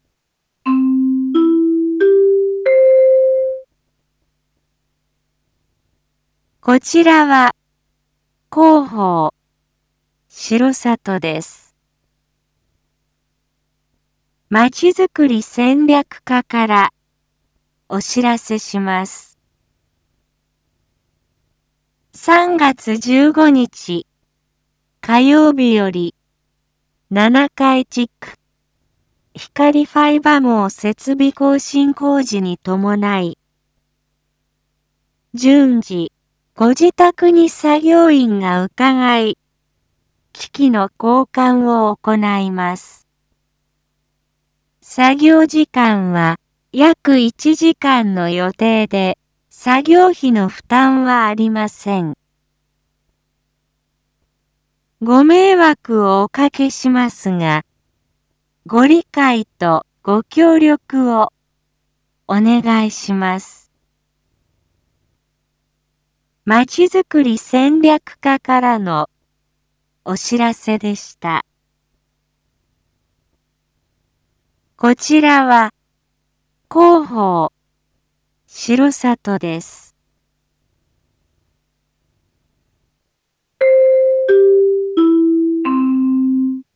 Back Home 一般放送情報 音声放送 再生 一般放送情報 登録日時：2022-03-14 19:01:28 タイトル：R4.3.14 19時 放送分 インフォメーション：こちらは広報しろさとです。